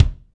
KIK FNK K03R.wav